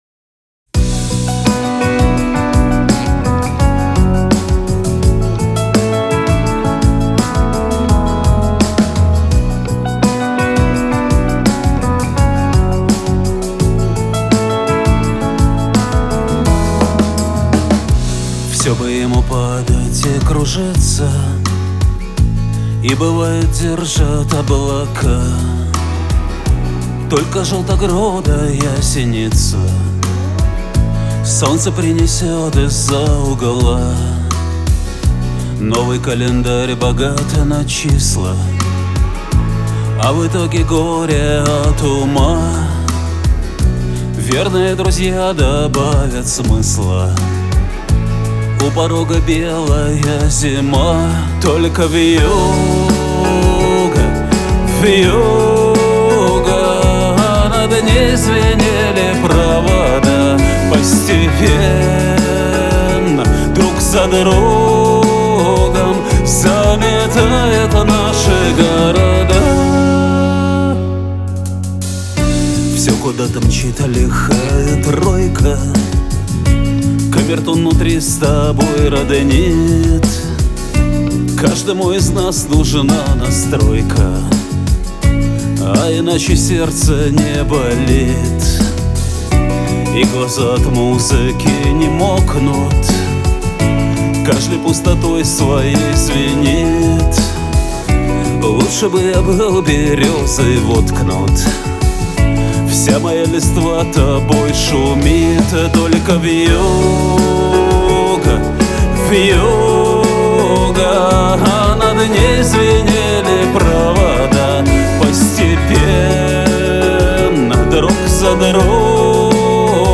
Категории: Русские песни, Рок.